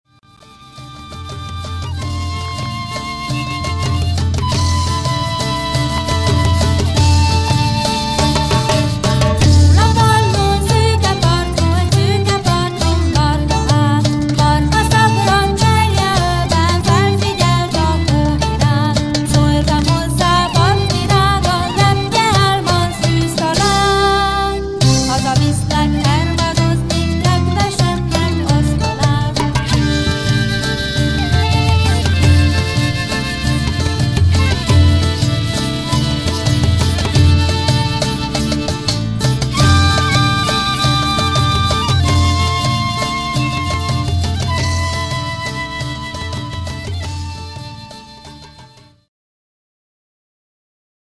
Traditional Afghan folk song